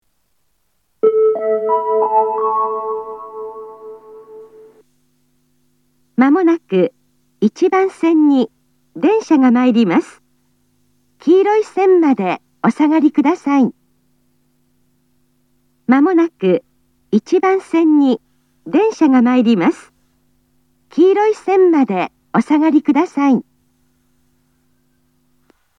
（女性）
接近放送
鳴動開始は到着約2分前です。